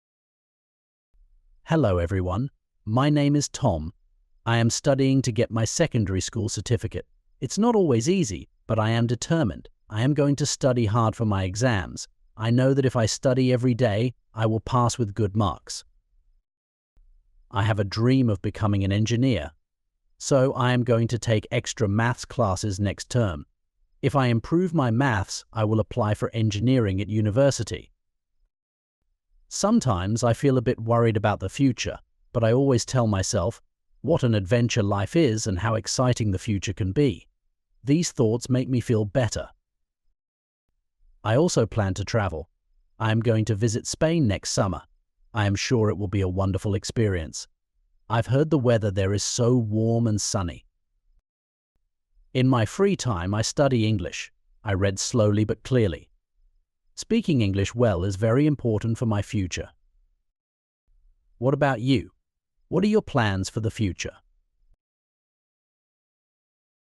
Tom is a student just like you, studying in an adult secondary school. He's excited about the future and has big plans.